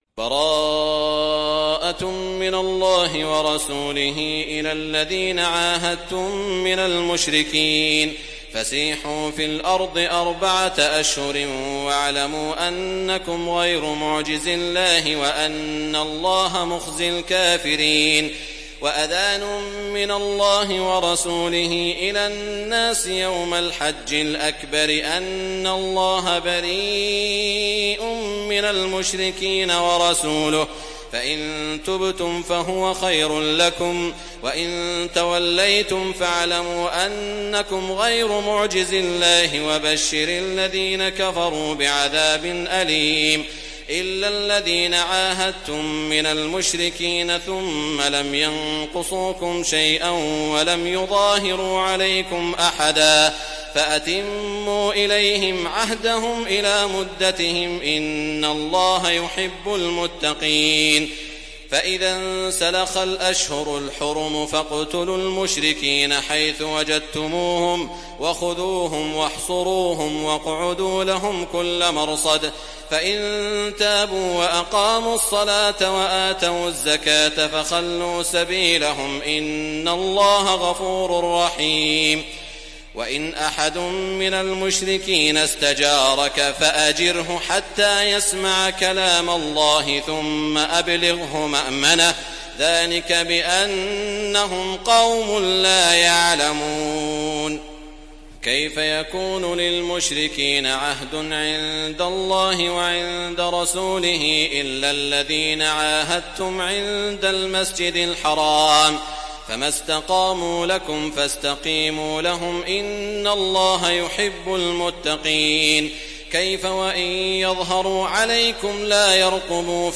Surat At Tawbah Download mp3 Saud Al Shuraim Riwayat Hafs dari Asim, Download Quran dan mendengarkan mp3 tautan langsung penuh